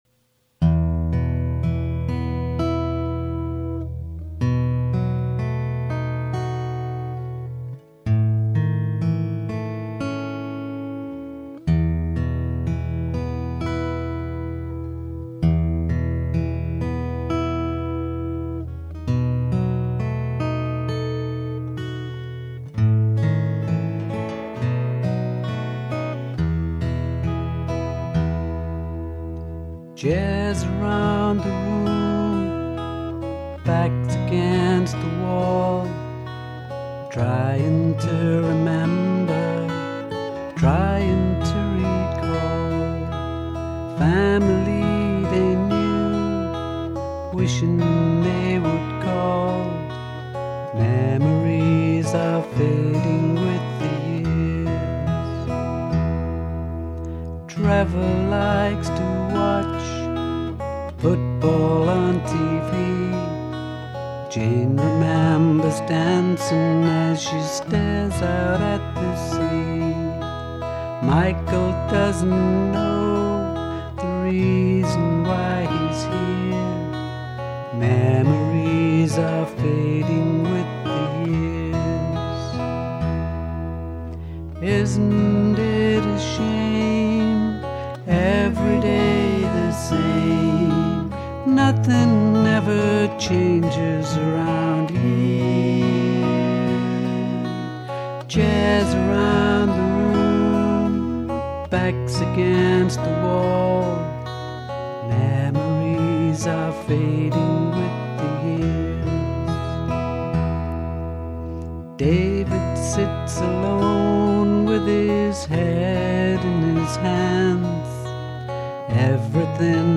He has an excellent voice, claiming that he is not a guitarist and the guitar is merely there as background support. His intricate playing, in a variety of styles, suggests otherwise.